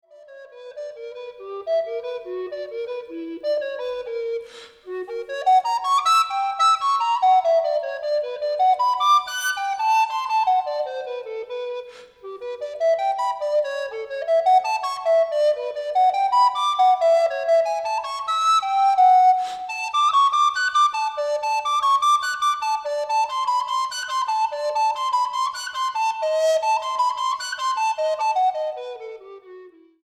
flautas de pico
para flauta sola